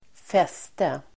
Uttal: [²f'es:te]